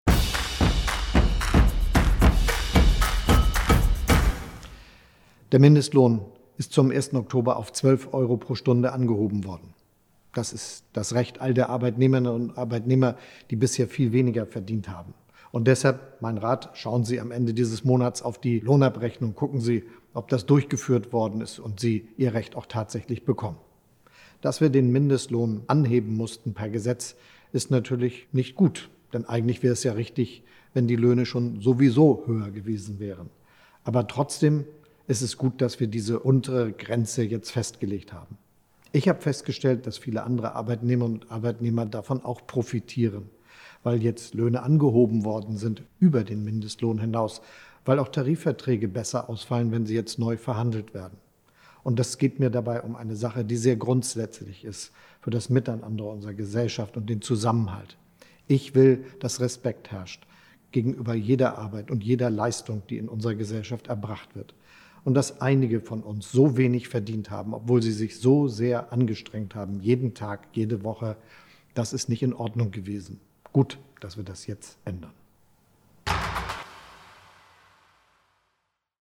Warum viele weitere Arbeitnehmerinnen und Arbeitnehmer davon profitieren – das erklärt Bundeskanzler Olaf Scholz in der neuen Folge von „Kanzler kompakt“.